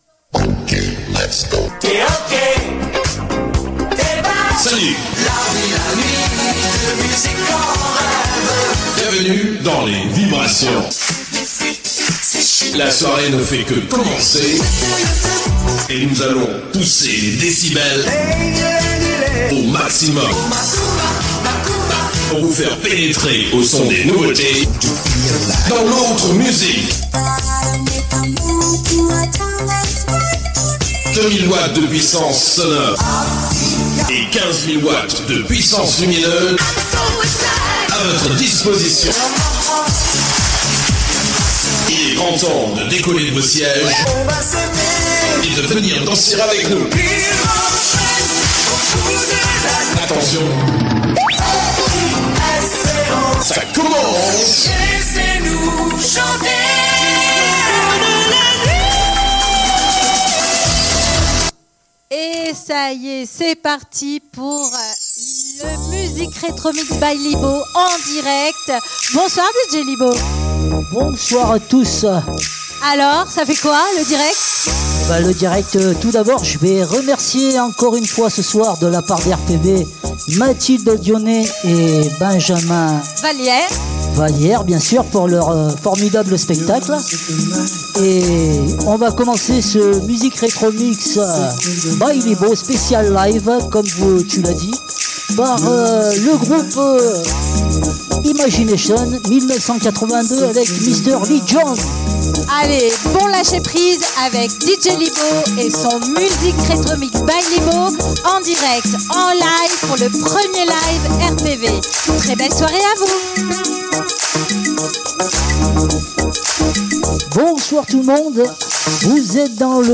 1ER LIVE
MUSIC RETRO MIX EN PUBLIC